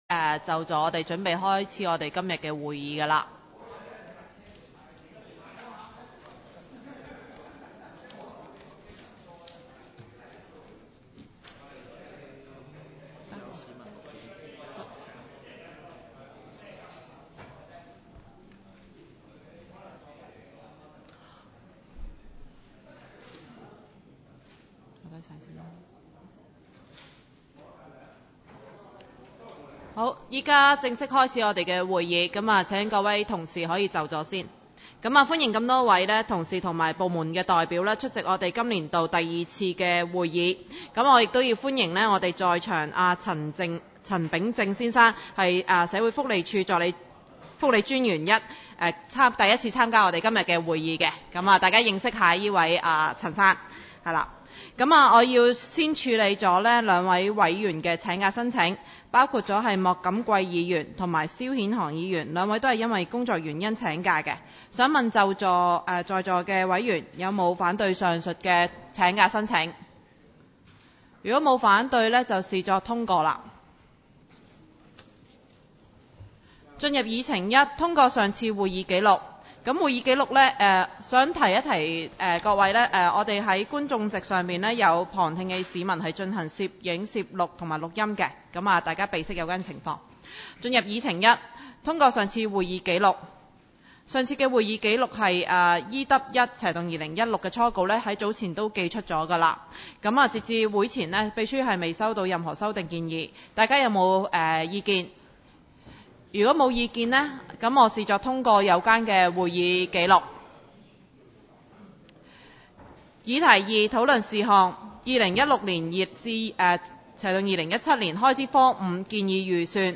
委员会会议的录音记录
教育及福利委员会第二次会议 日期: 2016-03-01 (星期二) 时间: 下午2时30分 地点: 沙田区议会会议室 议程 讨论时间 I. 二零一六/二零一七年度开支科5建议预算 00:02:21 II.